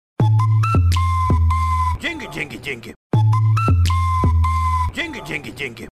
Здесь вы найдете забавные, мотивирующие и необычные аудиоэффекты, которые можно использовать при получении донатов.